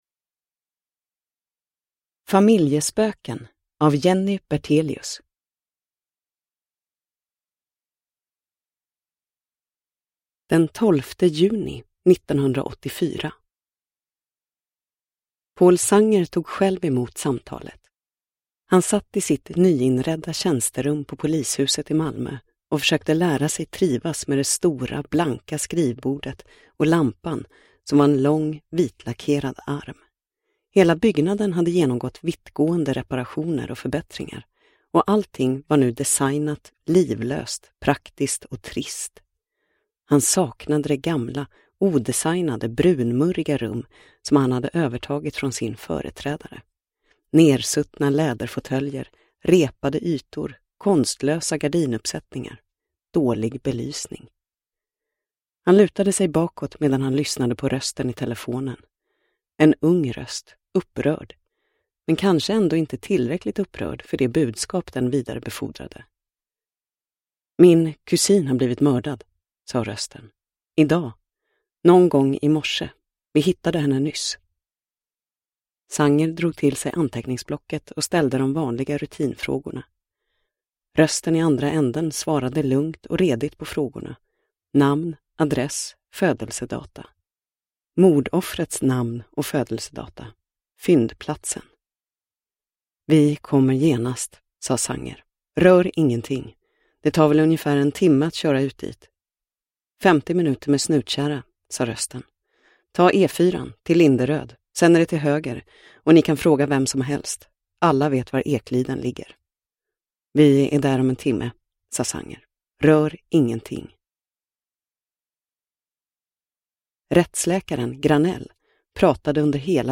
Familjespöken – Ljudbok – Laddas ner